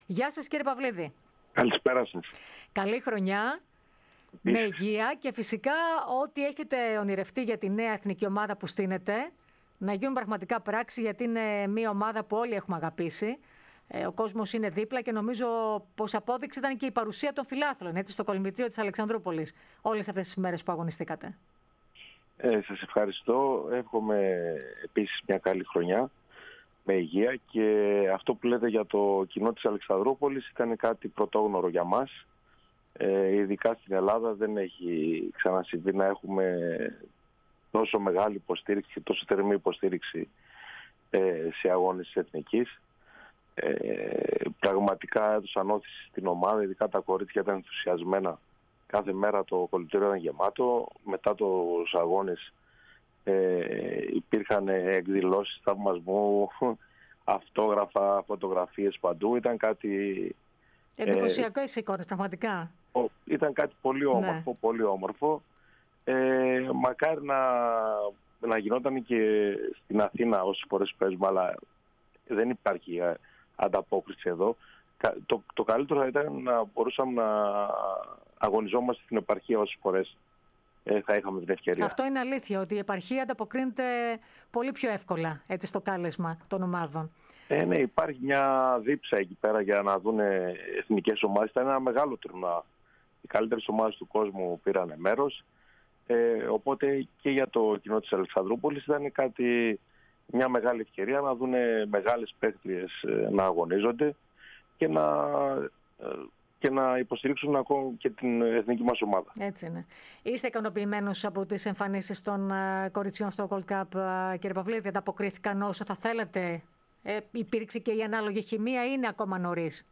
Aκούστε αναλυτικά την ενδιαφέρουσα συνέντευξη